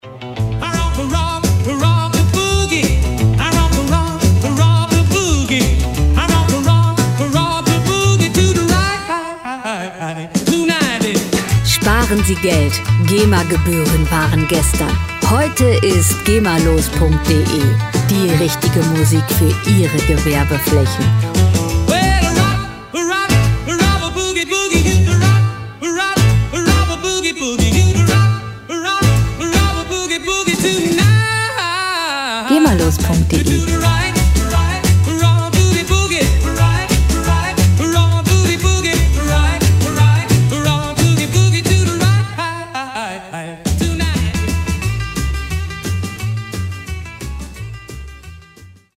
Musikstil: Rock 'n' Roll
Tempo: 175 bpm
Tonart: A-Dur
Charakter: nostalgisch, zeitlos